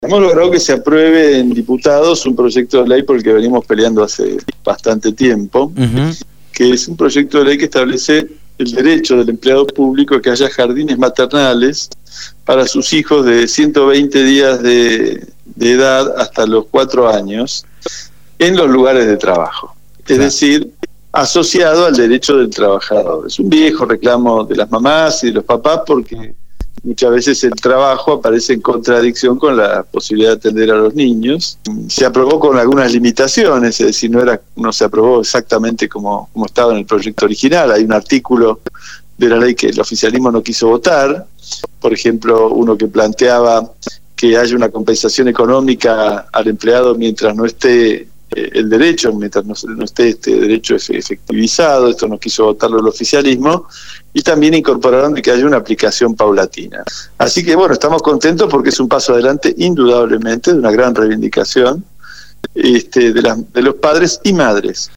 «Hemos logrado que se apruebe en Diputados un proyecto de ley por el que venimos peleando hace bastante tiempo, que es un proyecto de ley que establece el derecho del empleado público a que haya jardines maternales para sus hijos de 120 días de edad hasta los 4 años en los lugares de trabajo, es decir asociado al derecho del trabajador, es un viejo reclamo de las mamás y de los papás porque muchas veces el trabajo aparece en contradicción con la posibilidad de atender a los niños«, manifestó Del Pla a Radio Dinamo.